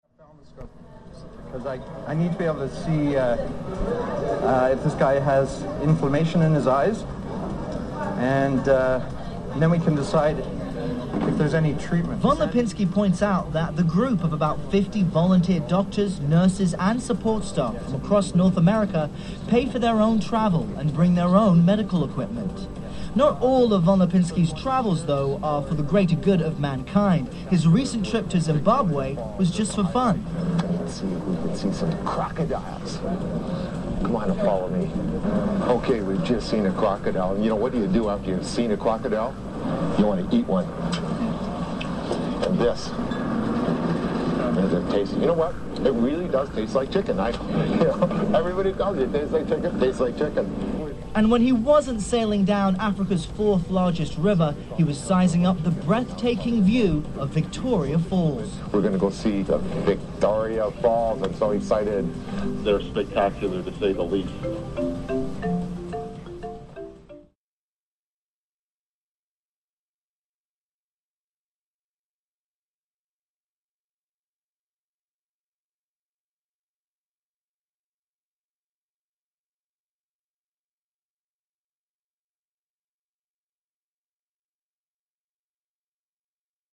今のところほぼ生音。
ニュース ニュースinア メリカ 01.26